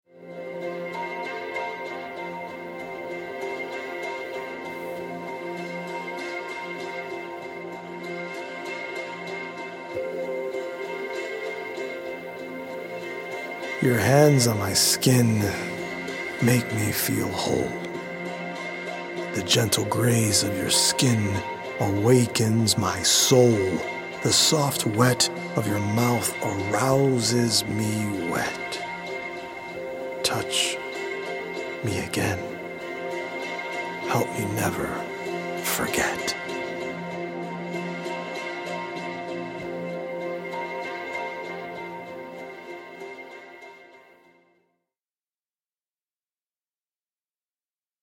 healing Solfeggio frequency music